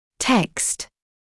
[tekst][тэкст]текст; статья; печатная работа